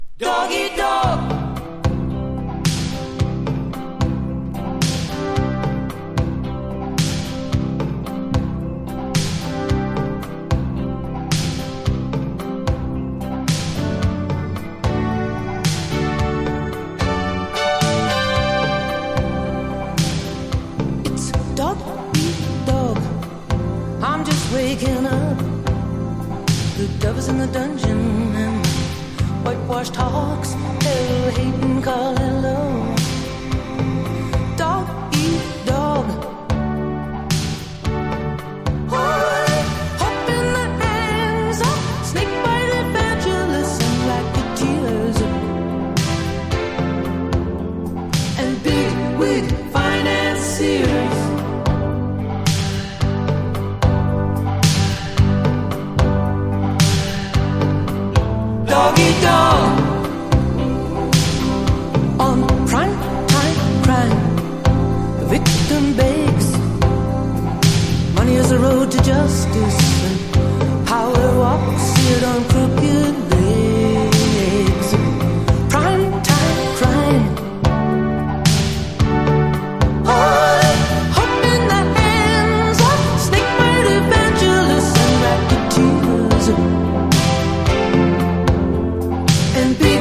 ニューウェイヴなサウンドに負けず個性的な彼女の声が響く素晴らしい1枚!!